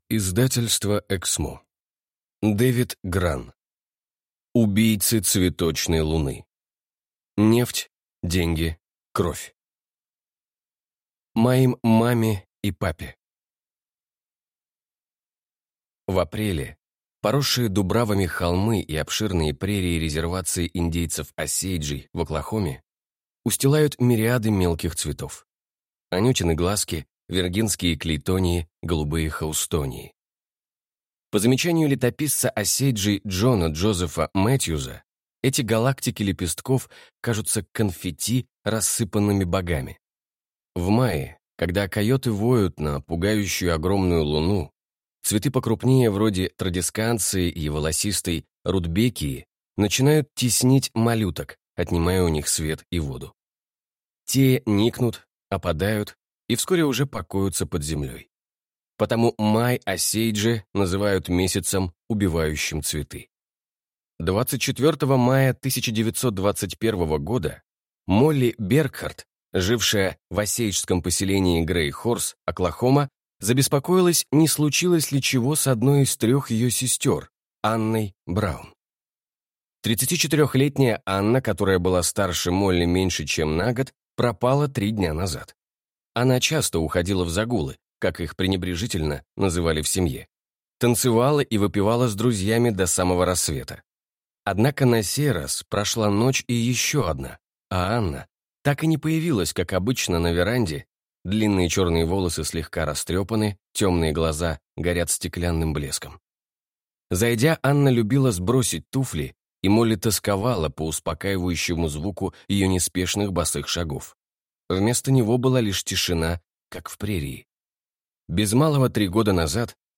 Аудиокнига Убийцы цветочной луны. Нефть. Деньги. Кровь | Библиотека аудиокниг